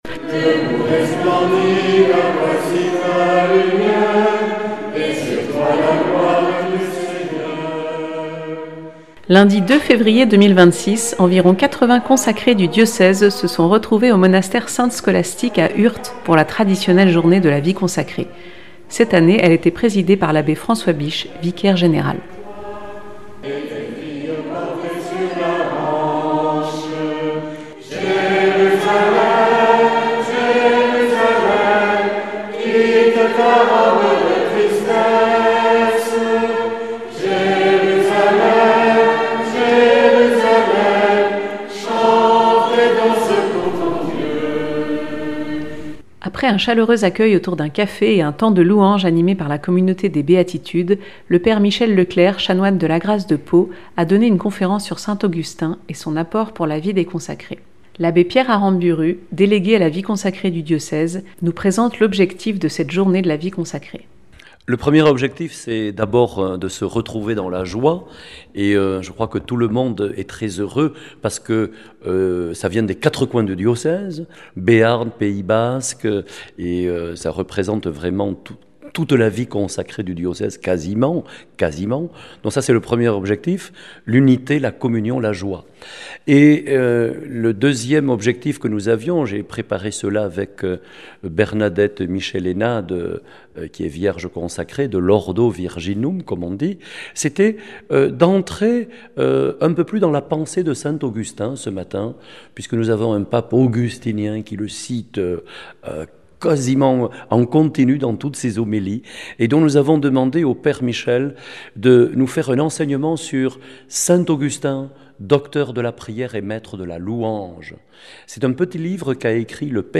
Reportage à la Journée de la vie consacrée qui a eu lieu à Urt le 2 février 2026, avec environ 80 consacrés du diocèse.